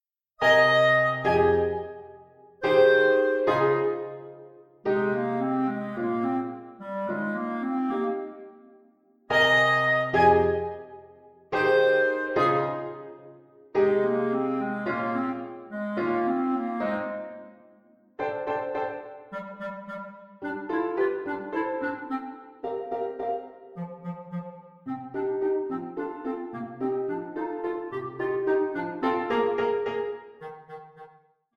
Clarinet and Piano